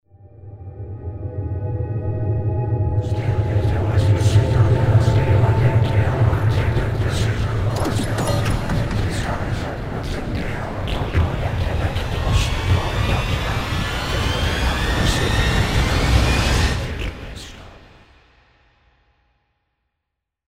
Sound Effect Horror Intro 4.mp3